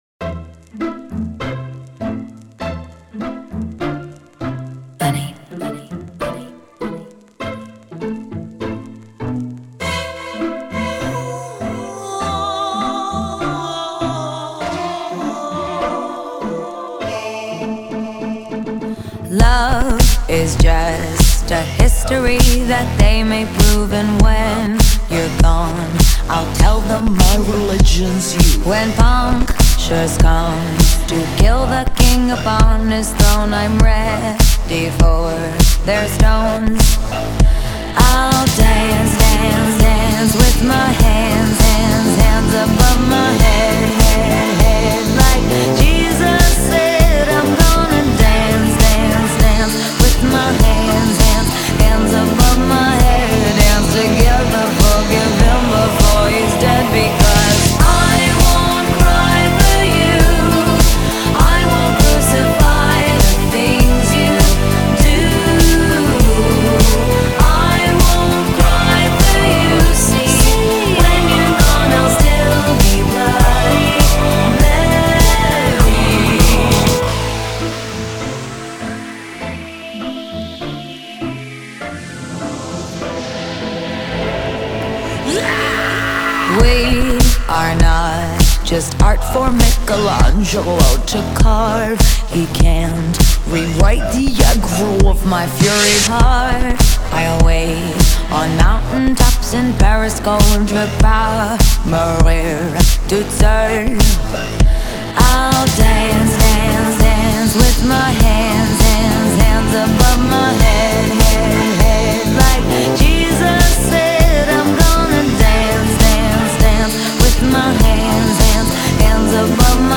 ریمیکس آهنگ {متن اصلی و ترجمه فارسی} Bloody خونین Oh اوه